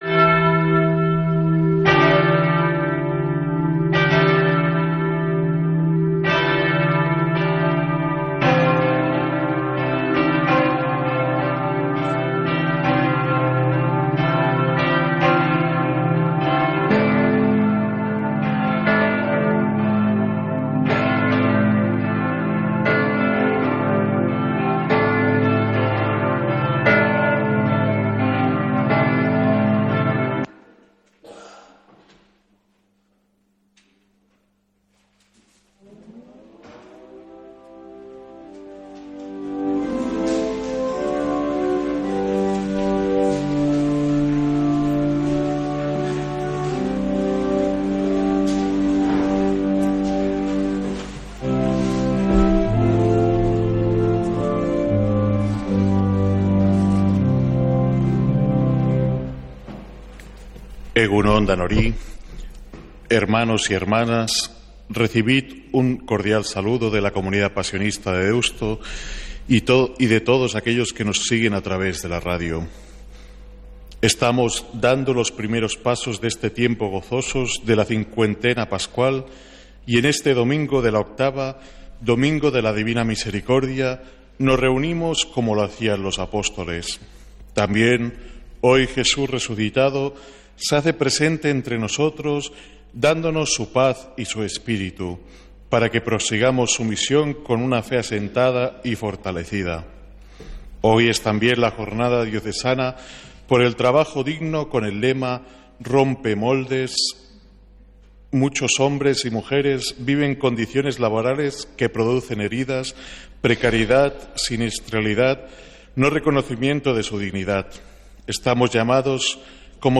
Santa Misa desde San Felicísimo en Deusto, domingo 27 de abril